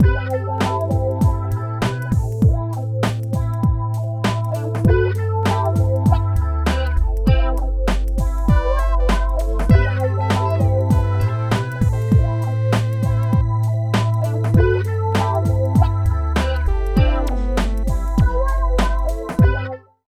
70 LOOP   -R.wav